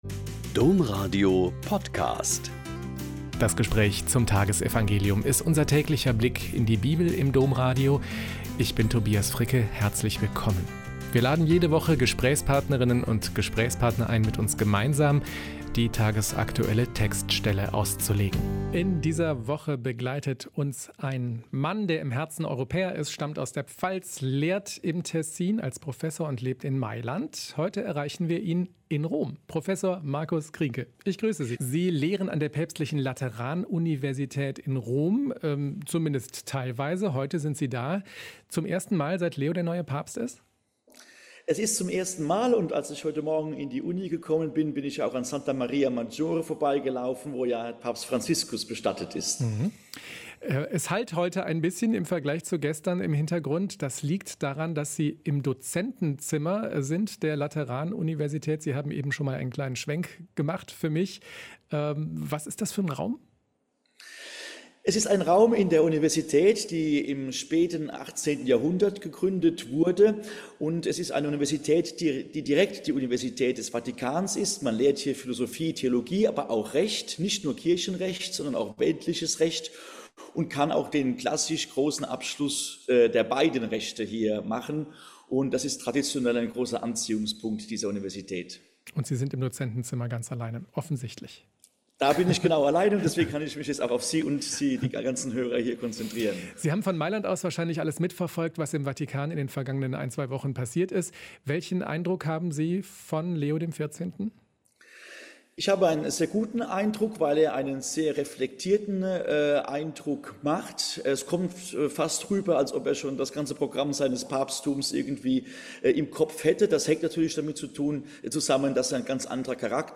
Joh 15,1-8 - Gespräch